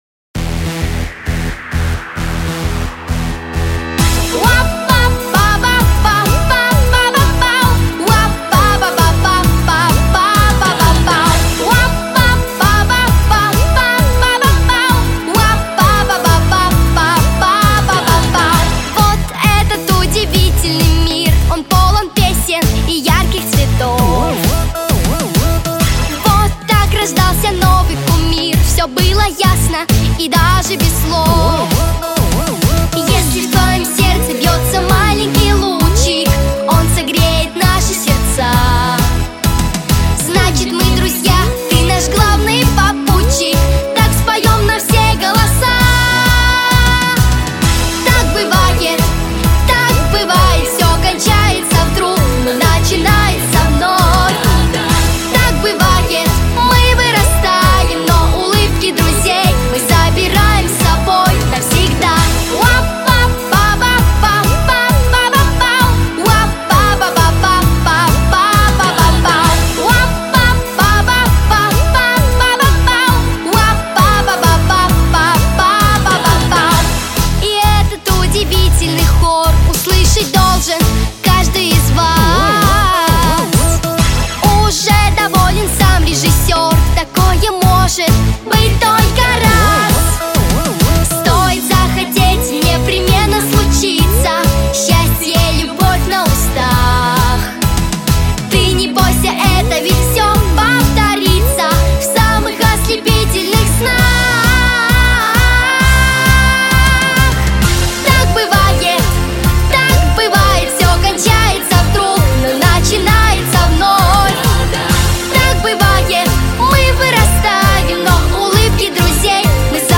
• Категория: Детские песни
Детский эстрадный ансамбль